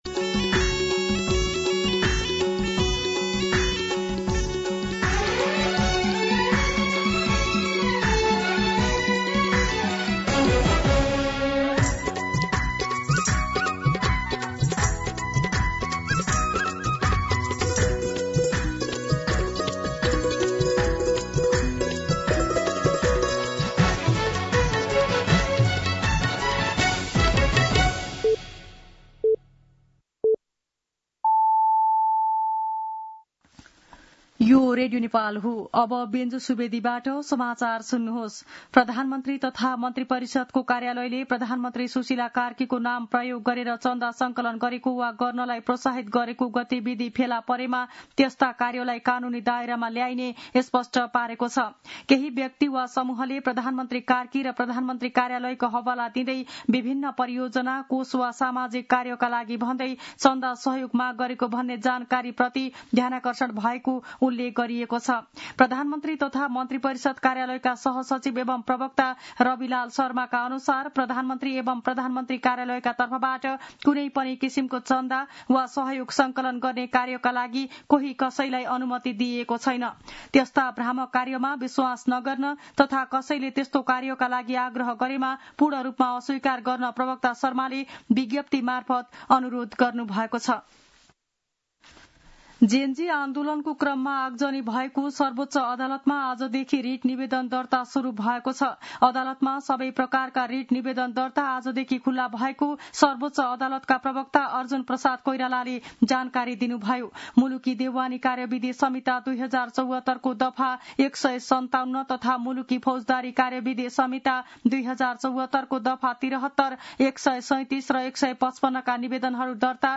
दिउँसो १ बजेको नेपाली समाचार : २८ असोज , २०८२
1pm-News-06-28.mp3